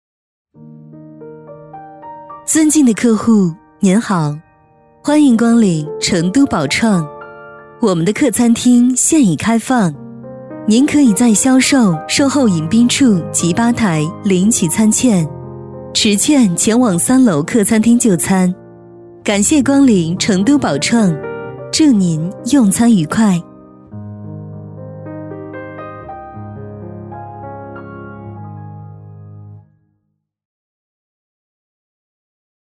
女119-餐厅提醒《宝马4S店-店内提示》-知性温柔.mp3